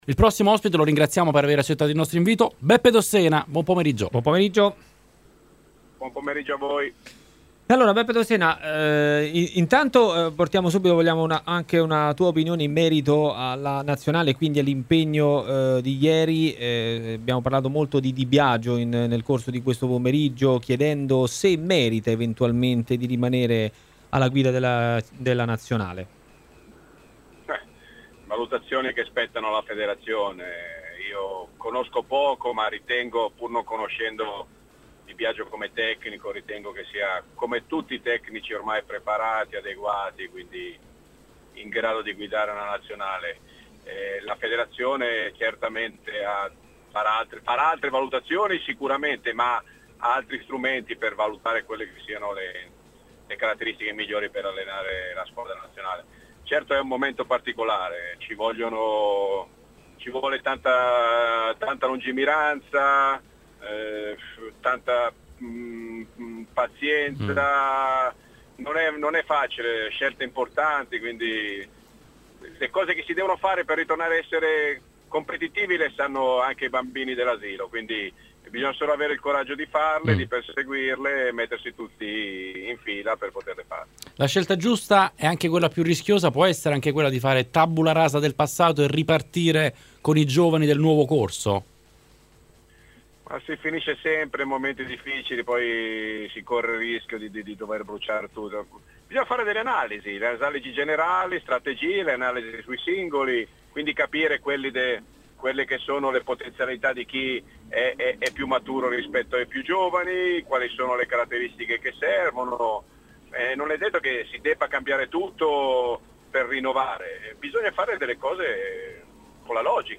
Beppe Dossena intervistato